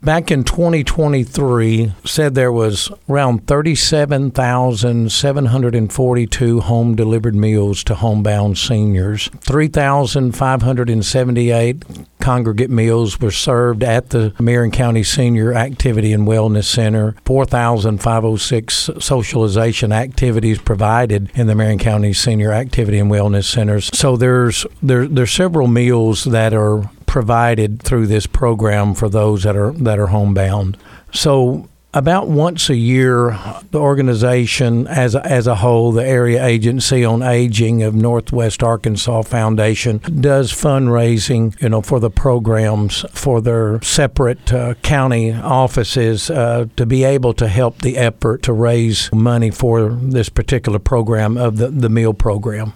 Flippin Mayor Heith Hogan spoke with KTLO, Classic Hits and The Boot News and says the funds raised will benefit the Meals on Wheels program at the senior center.